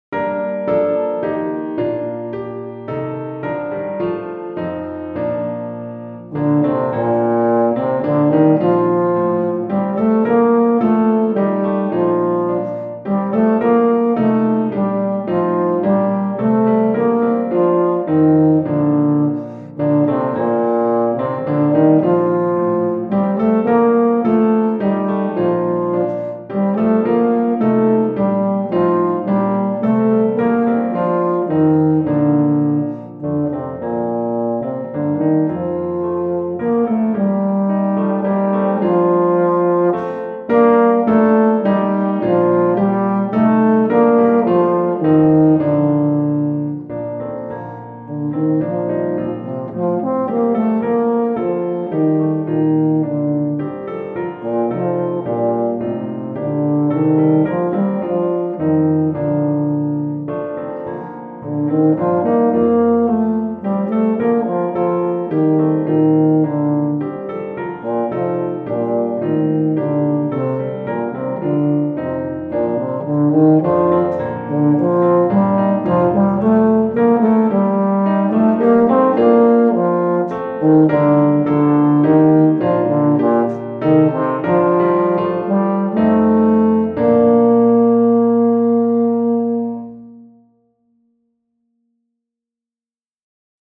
Euphonium et Piano